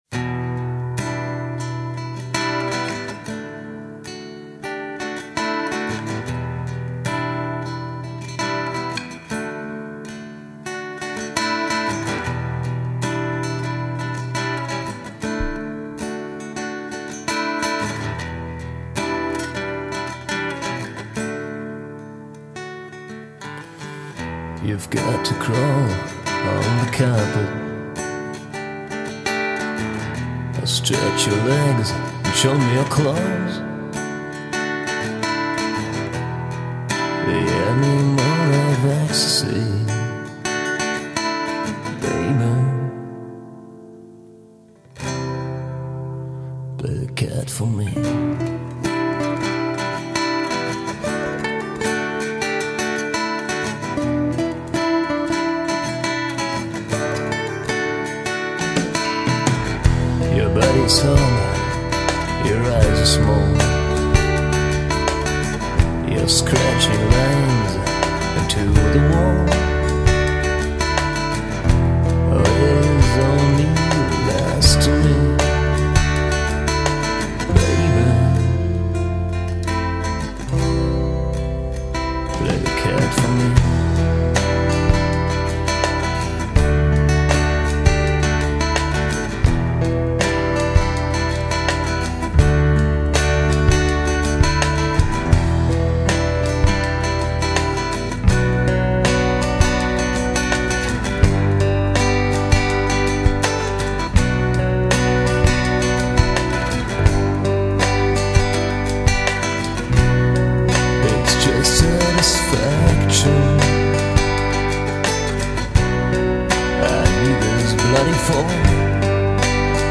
bei meinen Eltern im Keller